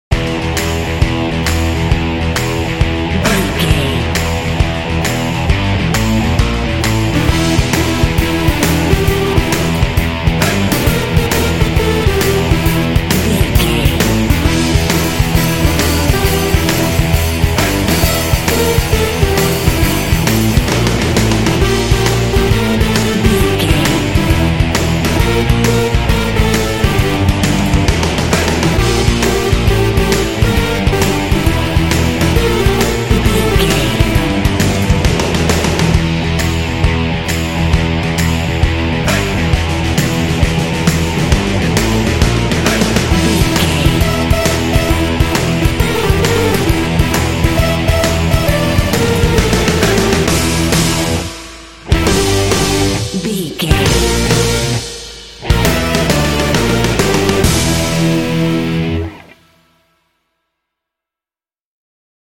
Aeolian/Minor
driving
funky
groovy
energetic
bouncy
bass guitar
electric guitar
drums
synthesiser
percussion
synth- pop
alternative rock